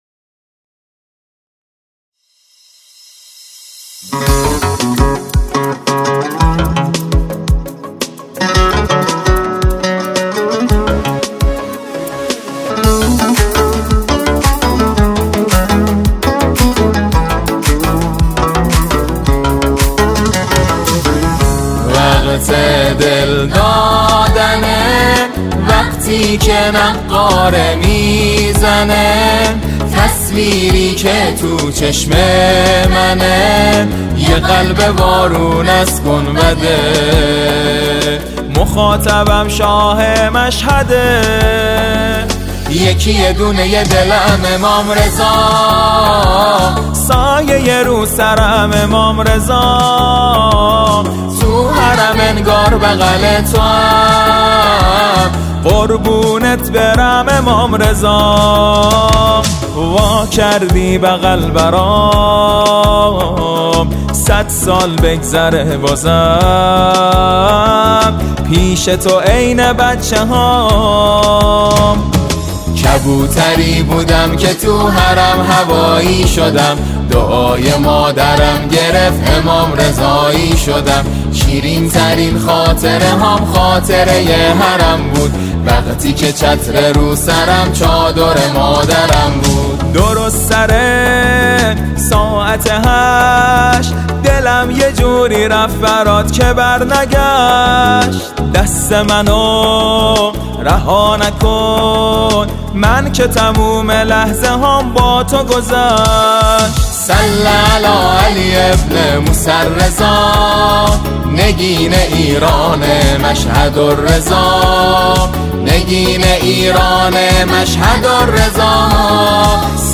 نماهنگ بسیار زیبا و شنیدنی